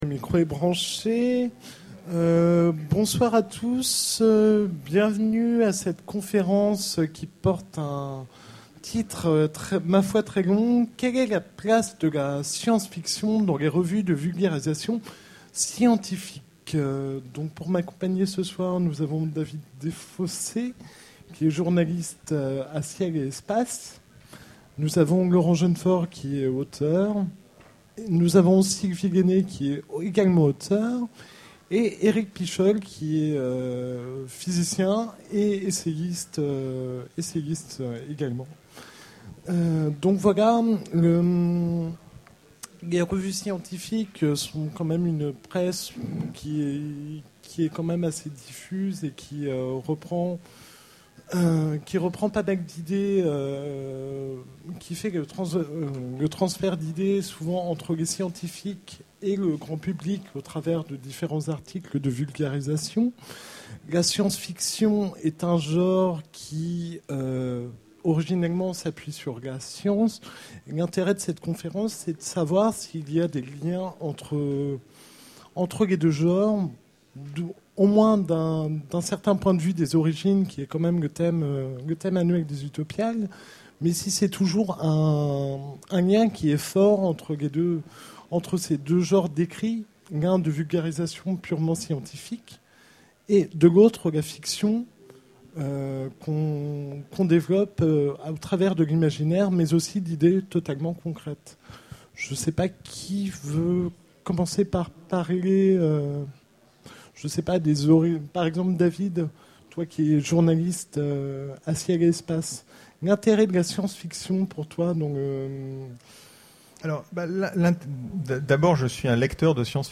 Utopiales 12 : Conférence Quelle est la place de la science-fiction dans les revues de vulgarisation scientifique ?